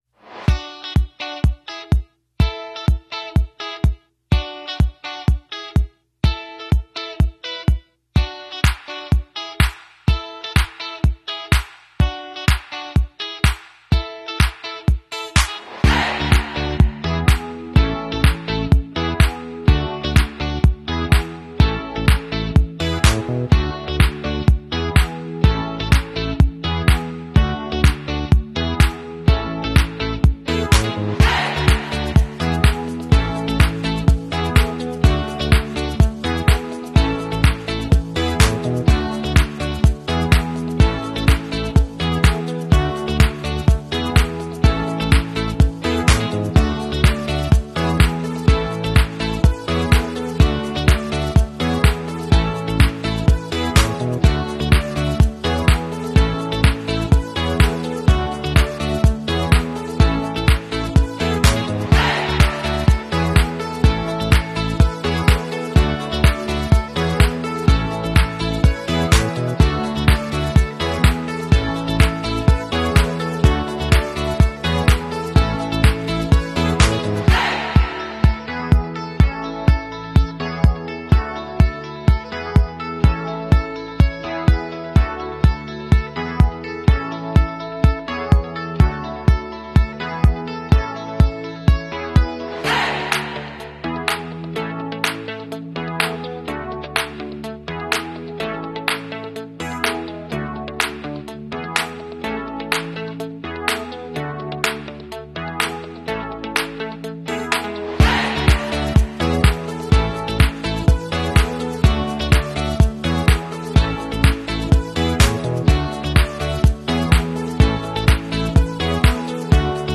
Amazing Flying Fen With Paper sound effects free download